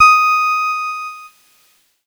Cheese Note 21-D#4.wav